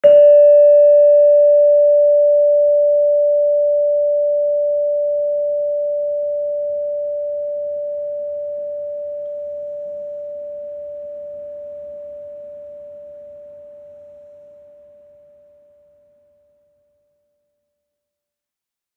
Gender-2-D4-f.wav